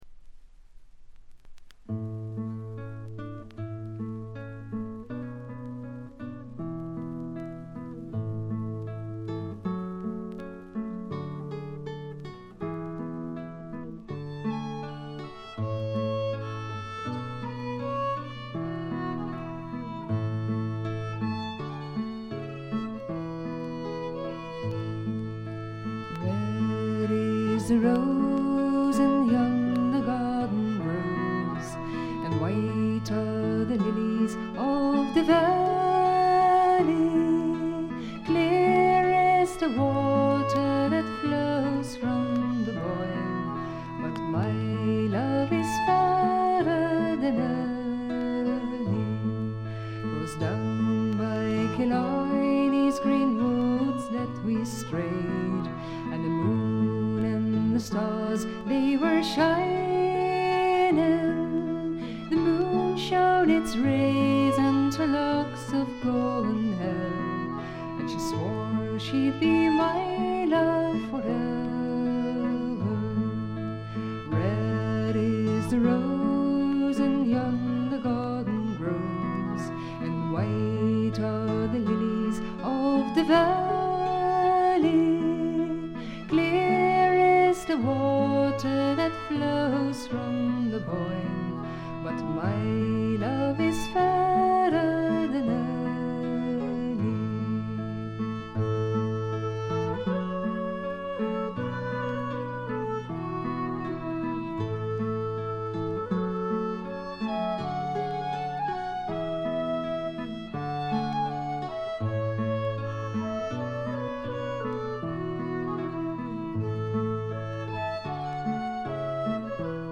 バックグラウンドノイズ、チリプチ多め大きめ。
オランダのトラッド・フォーク・グループ
試聴曲は現品からの取り込み音源です。